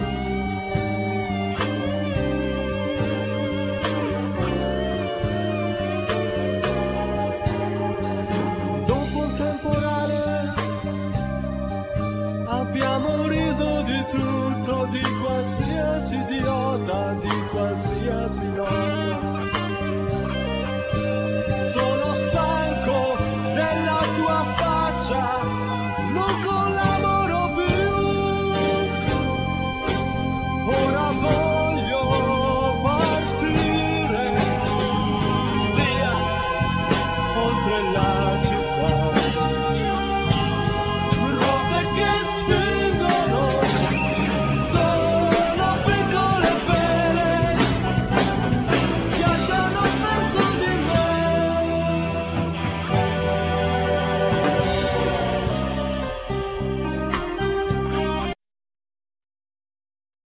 Drums
Keyboards
Vocals
Guitar
Bass
Tenor Saxophone
Backing vocals
Timpani